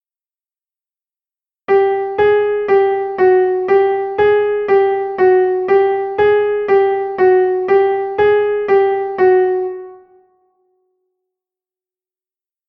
Anula os efectos dos anteriores.